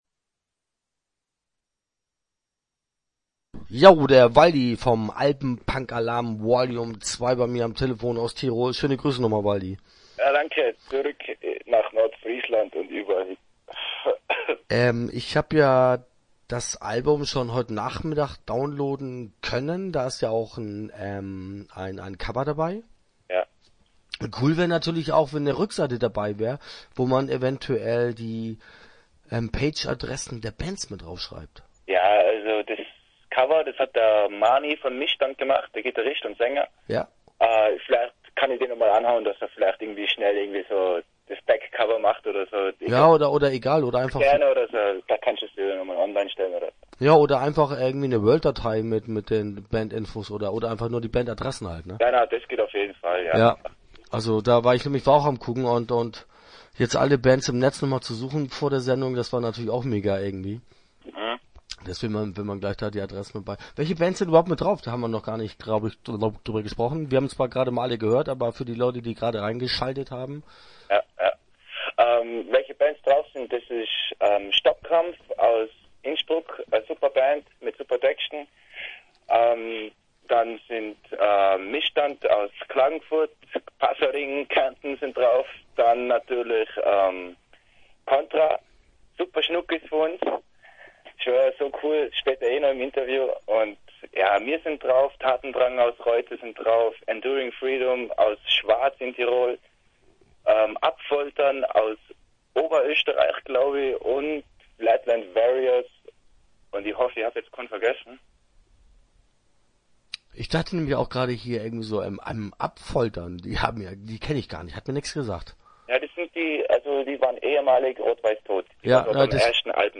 Start » Interviews » Alpenpunkalarm II